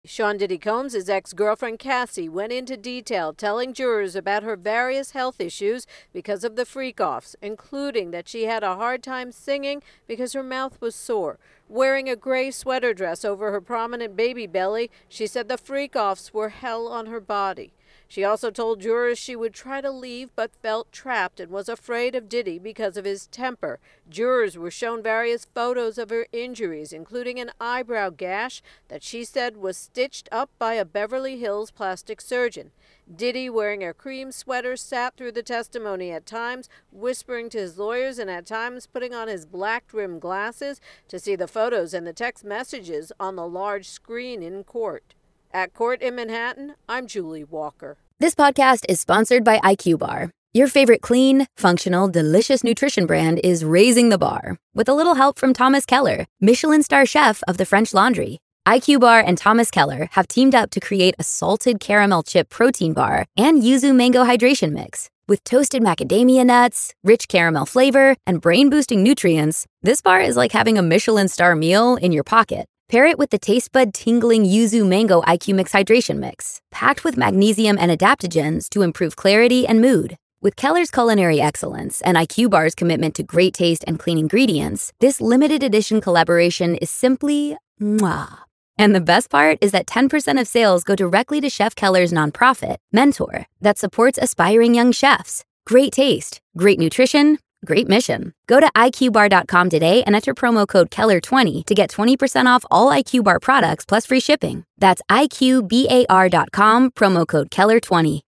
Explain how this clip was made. reports from court